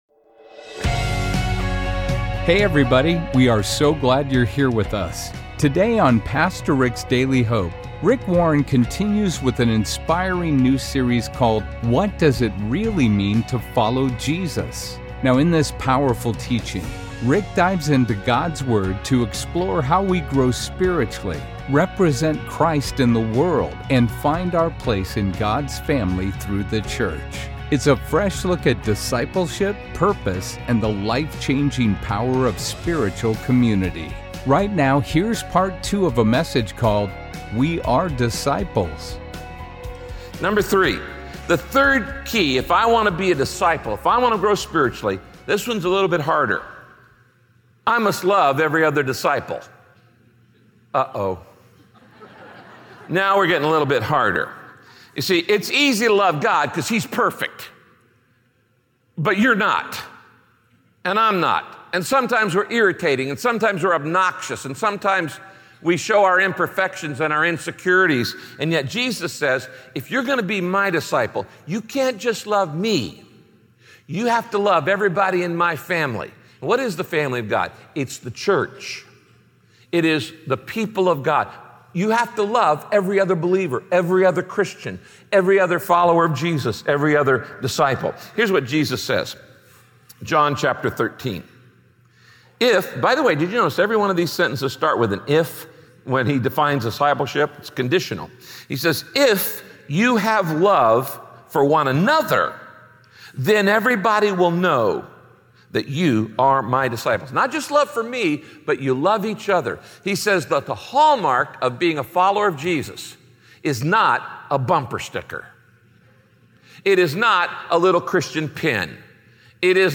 No one ever said being a disciple of Jesus would be easy. In this teaching, Pastor Rick explains how being Jesus' disciple means loving other believers and alwa…